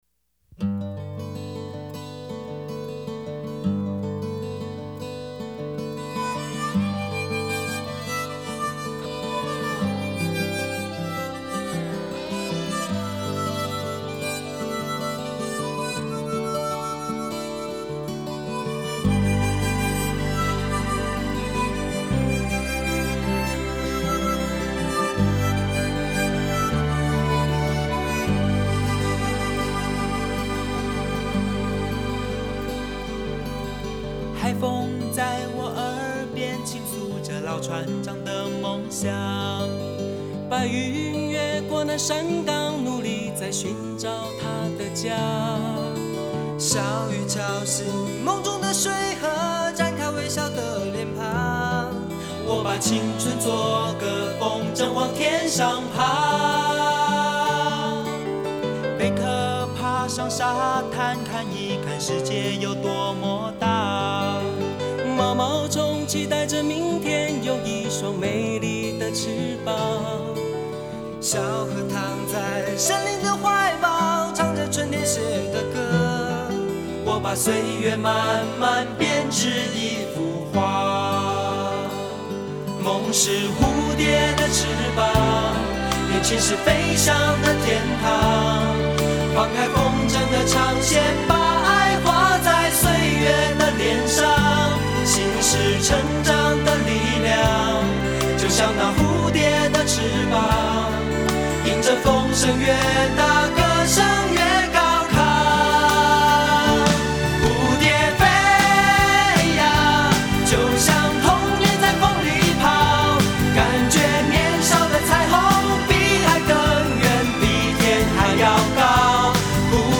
电音 收藏 下载